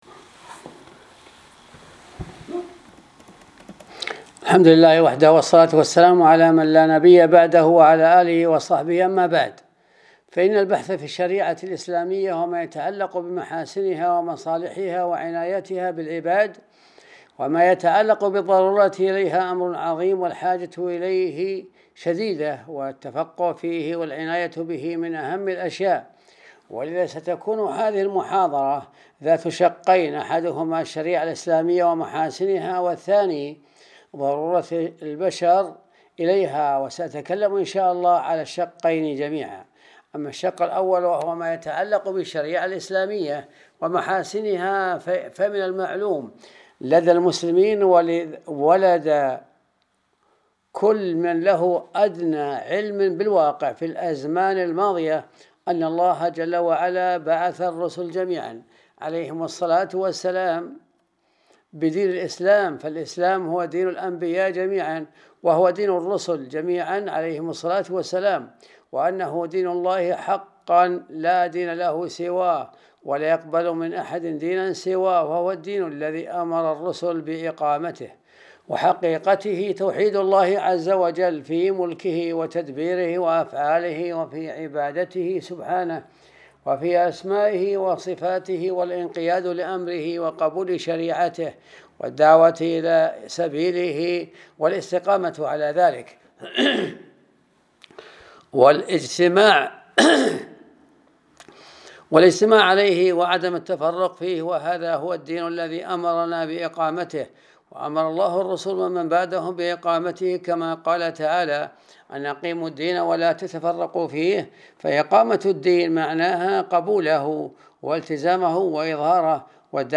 الدرس 64 الجزء الثاني ‌‌الشريعة الإسلامية ومحاسنها وضرورة البشر إليها